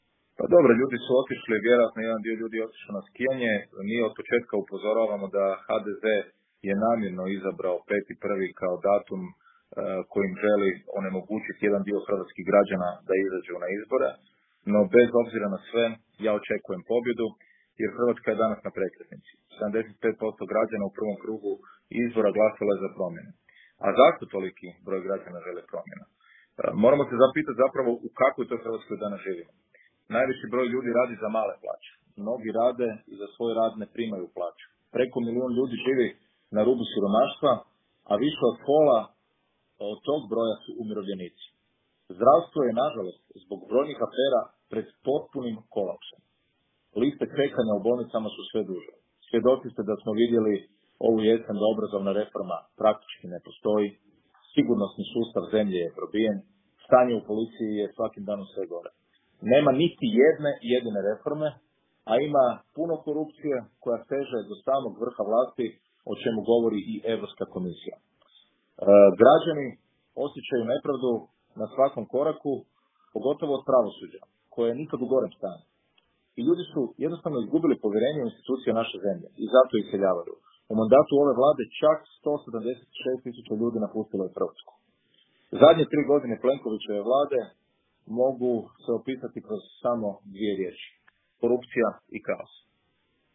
Davor Bernardić u intervjuu Media servisa o aktualnoj predsjedničkoj kampanji